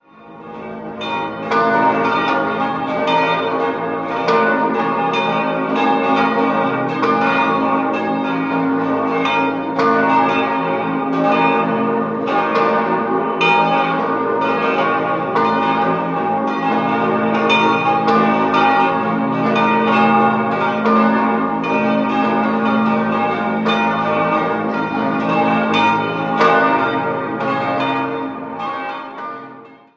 6-stimmiges ausgefülltes und erweitertes B-Moll-Geläute: b°-des'-es'-f'-as'-b'
Die Glocke es' ist historisch, alle anderen wurden von der Gießerei Otto in Bremen-Hemelingen gegossen.
Da es Aufnahmetag extrem windig war, musste ich einen windgeschützten Standort einnehmen, der aber genau frontal zu den Türmen lag, in Schwungrichtung der Glocken, was sich im Tonbeispiel negativ bemerkbar macht.
Waldsassen_Basilika.mp3